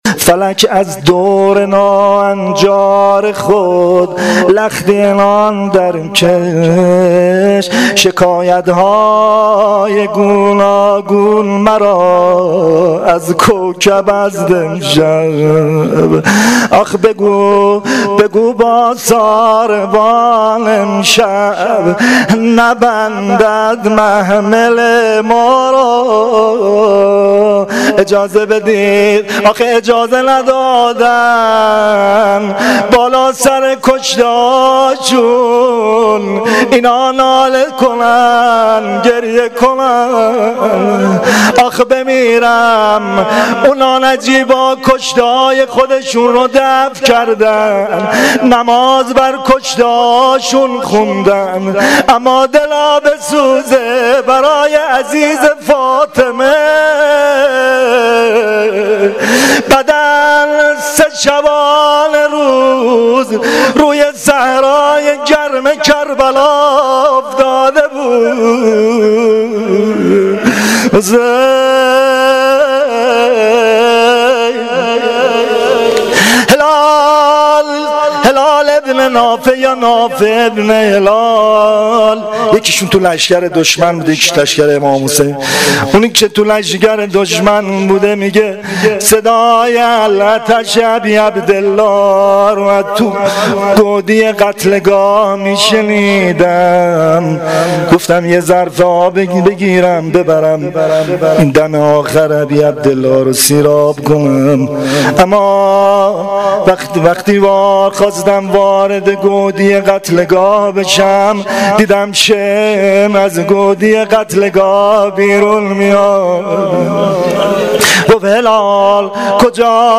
روضه شب یازدهم محرم الحرام 1396 (شام غریبان)
• Shabe11 Moharram1396[03]-Rouzeh.mp3